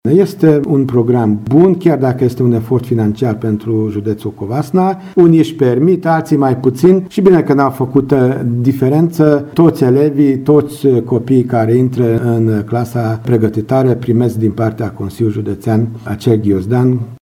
a declarat Kiss Imre, şeful Inspectoratului Şcolar Judeţean Covasna.